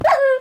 sounds / mob / wolf / hurt3.mp3
hurt3.mp3